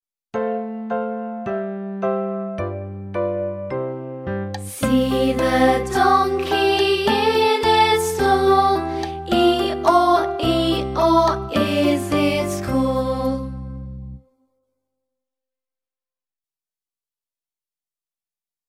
SONG /i:/ /o:/
Move them up and down as you say eeyore, eeyore…